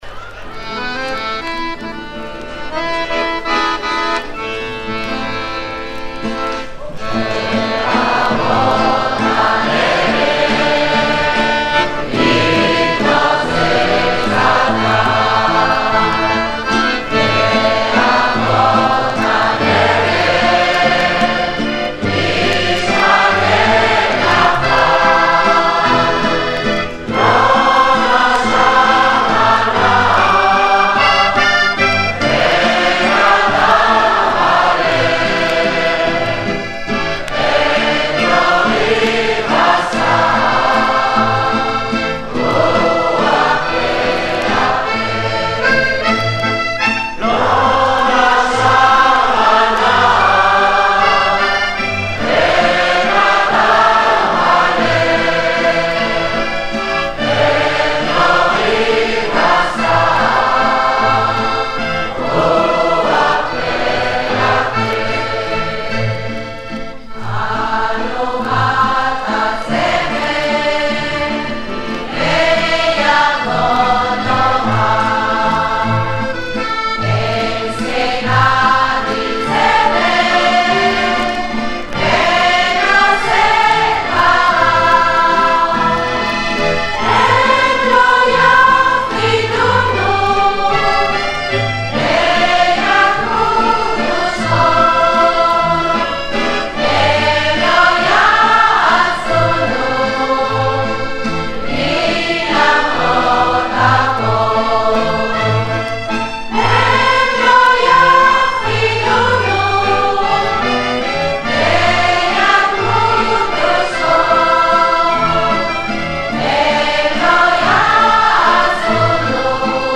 Музыка: народная Слова: народные